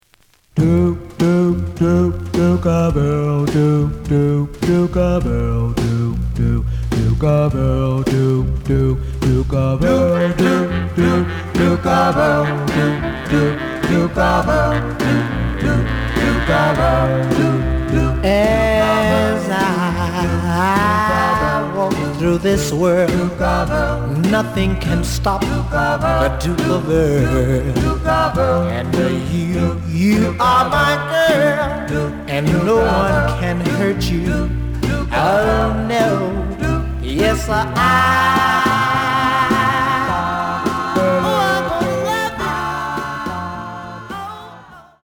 The audio sample is recorded from the actual item.
●Genre: Rhythm And Blues / Rock 'n' Roll
Some damage on both side labels. Plays good.)